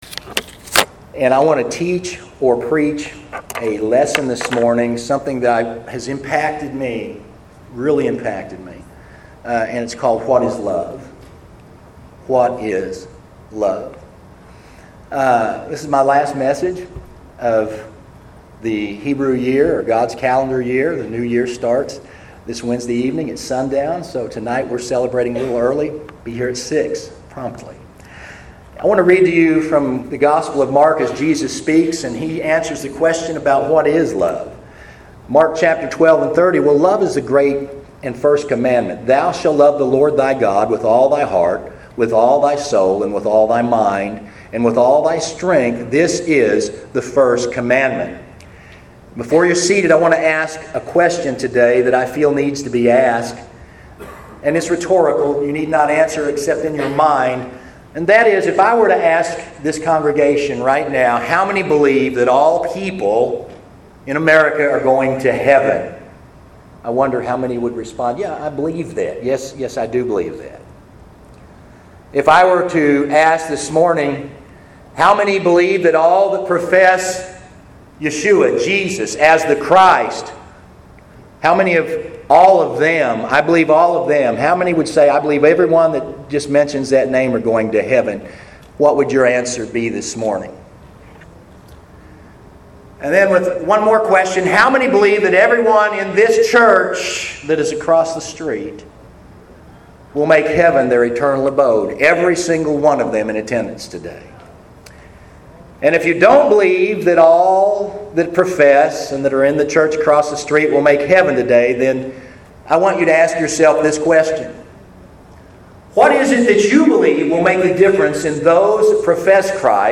This sermon has been released only because of many requests by those in attendance when it was delivered Sunday Sept. 21st 2014.